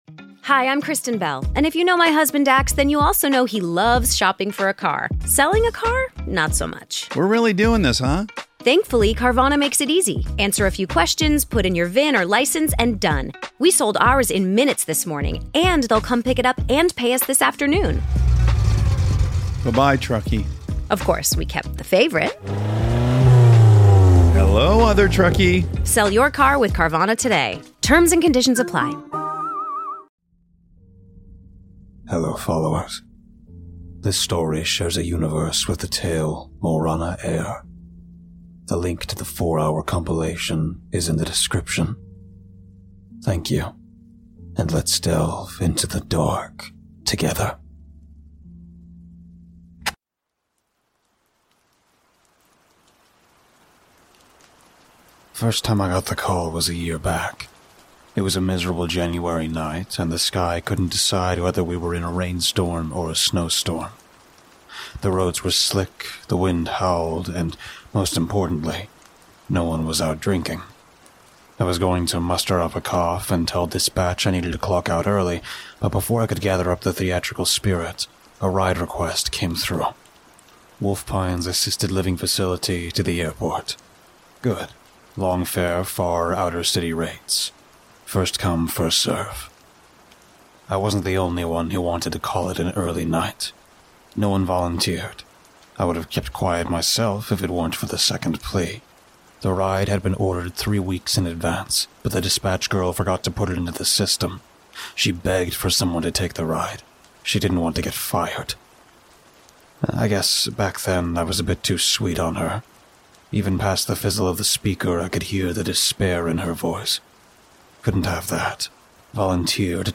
Sad Piano Music